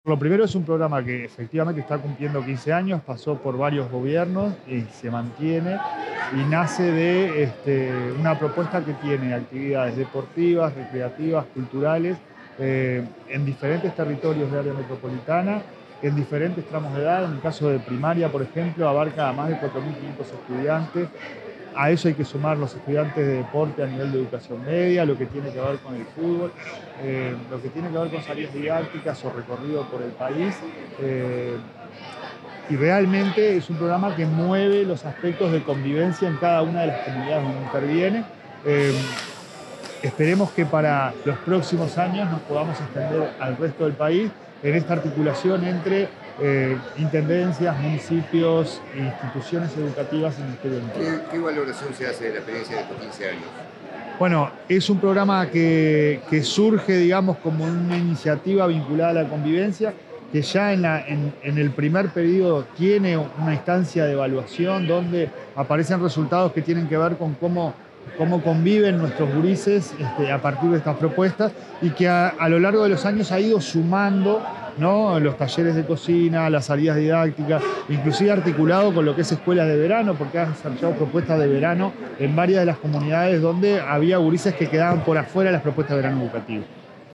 Declaraciones del presidente de la ANEP, Pablo Caggiani
Declaraciones del presidente de la ANEP, Pablo Caggiani 12/06/2025 Compartir Facebook X Copiar enlace WhatsApp LinkedIn El presidente de la Administración Nacional de Educación Pública (ANEP), Pablo Caggiani, dialogó con la prensa, luego de participar en la celebración del aniversario del programa Pelota al Medio a la Esperanza.